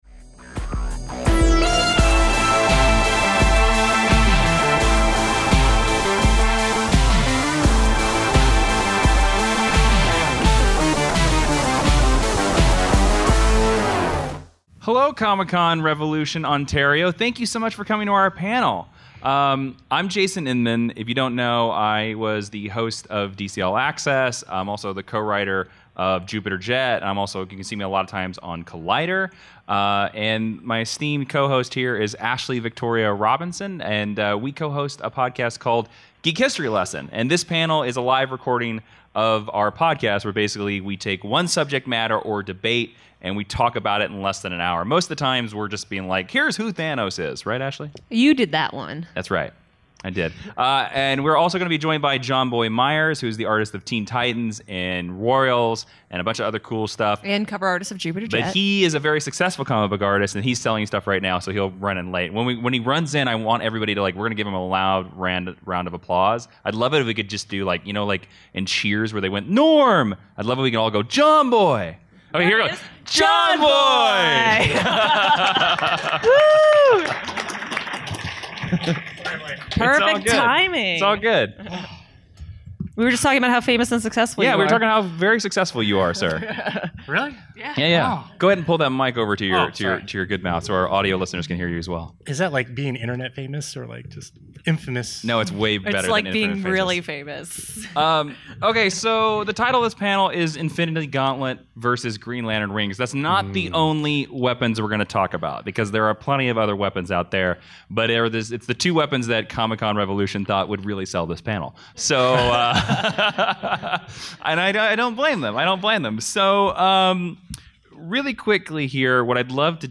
Recorded live at Ontario Comic Con Revolution 2018.